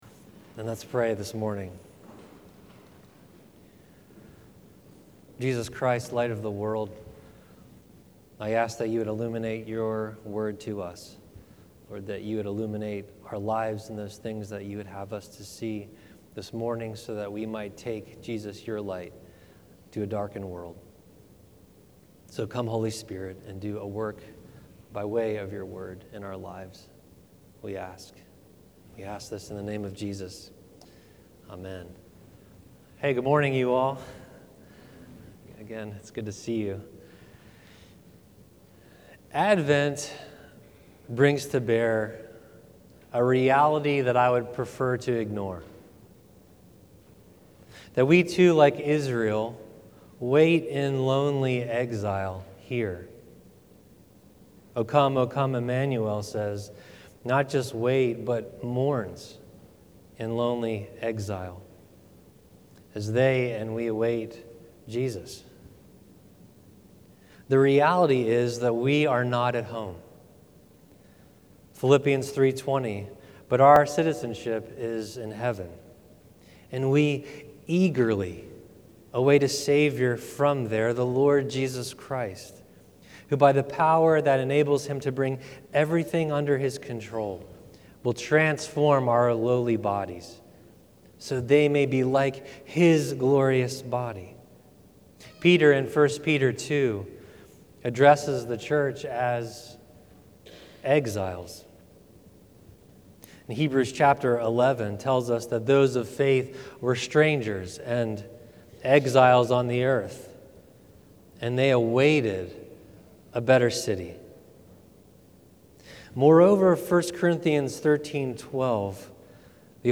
Sermons | Church of the Good Shepherd